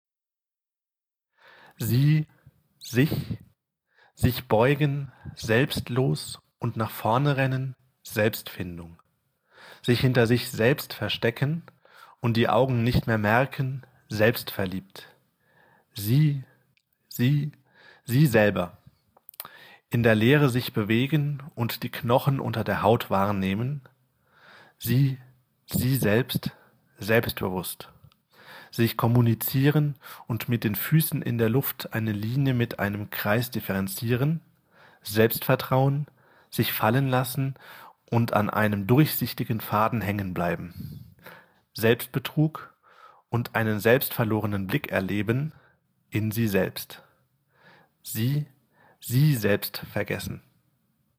Sie konjugiert ein Bewegungsvokabular, das mit einem Wortspiel synchronisiert ist, welches aus dem Lautsprecher ertönt, sowie spezifische Klänge, bei denen die Künstlerin sich selbst dazu zwingt, die stets gleichen Aktionen auszuführen.
Da für jede Aufführung eine andere Tonmischung erstellt wurde, in der die Elemente zufällig platziert wurden, ergab sich immer eine andere Choreographie.